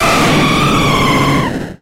Grito de Lugia.ogg
Grito_de_Lugia.ogg.mp3